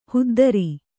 ♪ huddari